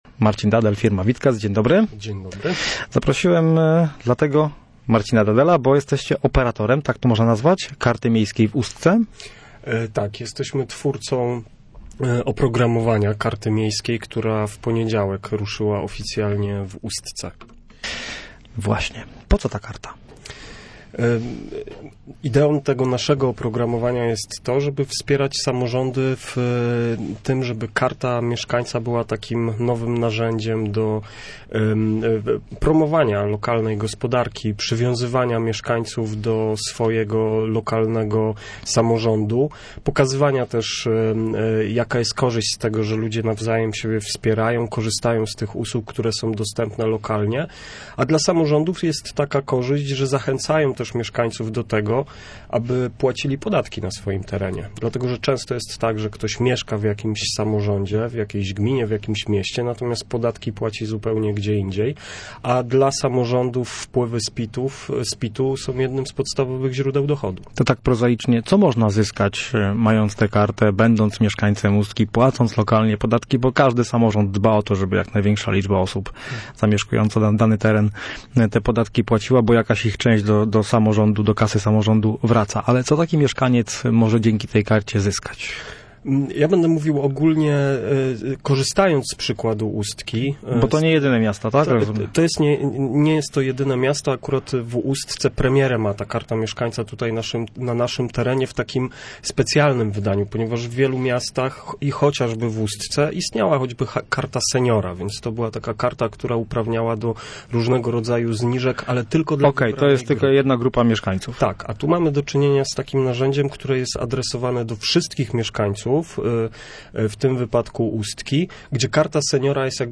Całej rozmowy można posłuchać tutaj: